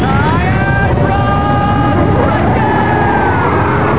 Kento of hardrock and his sure kill, "Iron Rock Crusher"